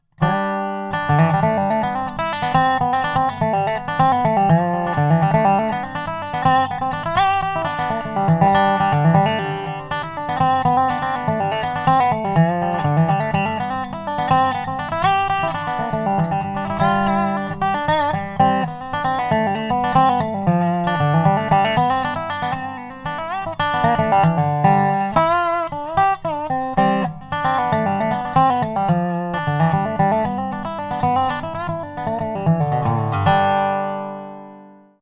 Díky smrkové přední desce je zvuk nástroje jemný a měkký. Tento nástroj se může pochlubit výbornou vyvážeností celkového zvuku.
Ve vysokých polohách je barva zvuku příjemně zakulacená a zároveň brilantní. Celkový zvuk je silný, barevně prokreslený a pestrý.